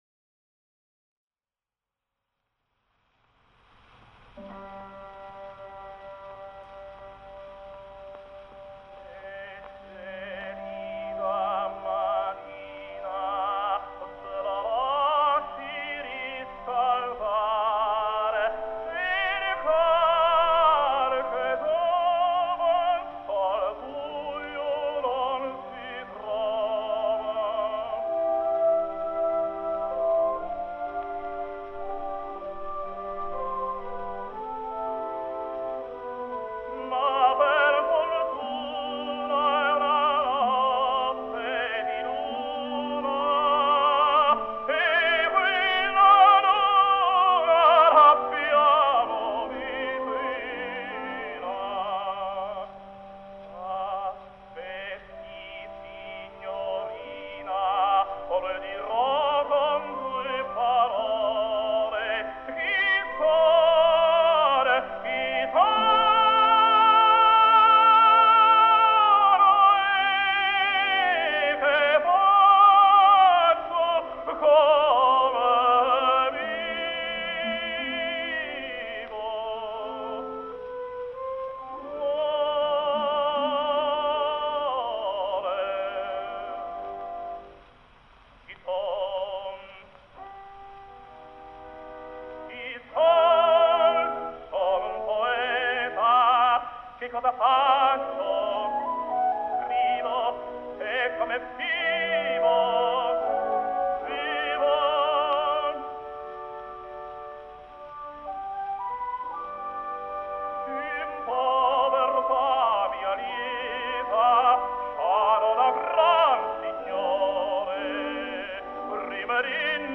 在表演抒情角色时，同样可以表现出感人的温情。他在声区的转换、呼吸、吐字等方面，具有惊人的高超技巧。